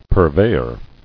[pur·vey·or]